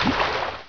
dsslime1.ogg